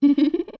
TP_Midna_Laugh2.wav